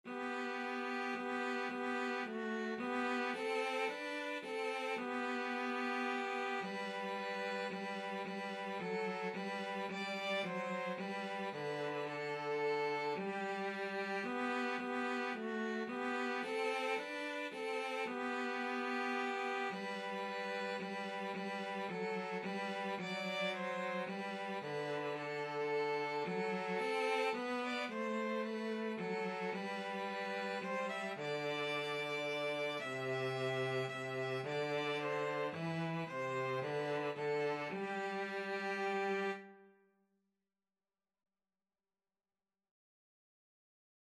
3/4 (View more 3/4 Music)
Traditional (View more Traditional Violin-Cello Duet Music)